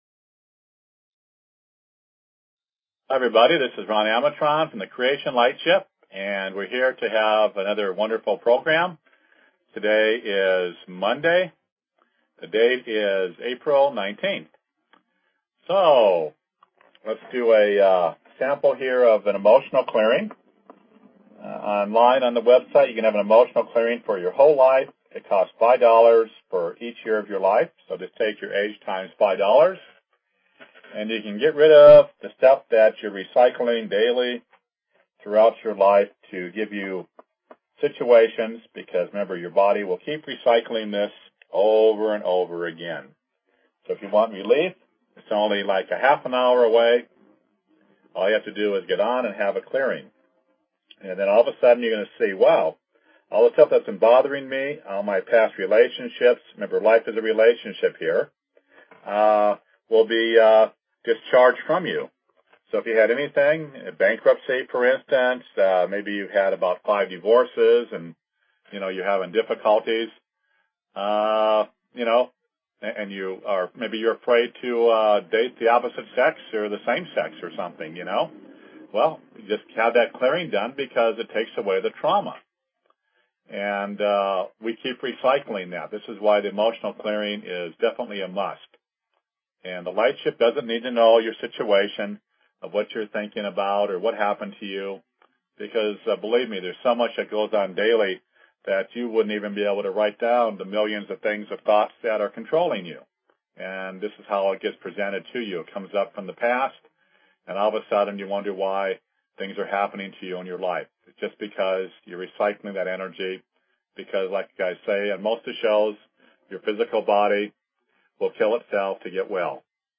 Talk Show Episode, Audio Podcast, Creation_Lightship_Healings and Courtesy of BBS Radio on , show guests , about , categorized as